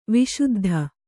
♪ viśuddha